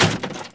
Wood_hit.ogg